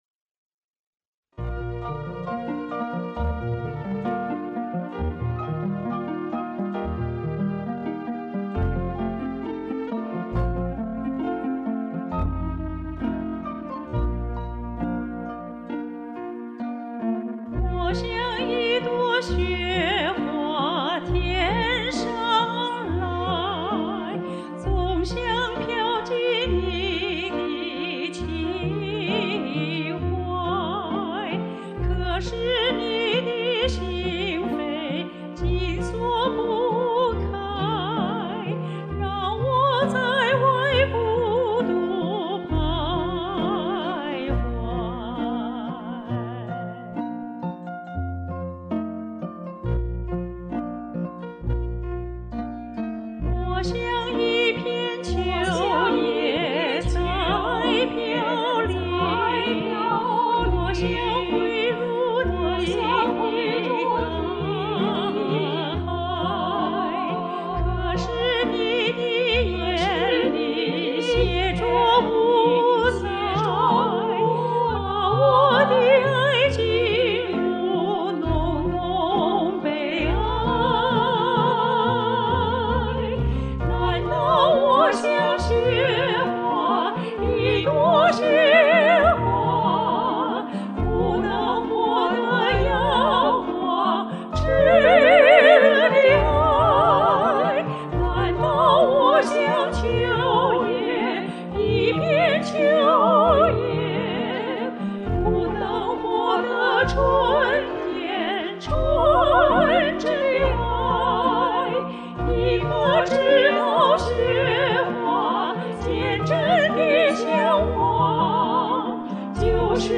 这也是我的第一首分别录音网络合成的歌。现在听来虽然从录音到合成都很幼稚，但是仍然是那么亲切感人。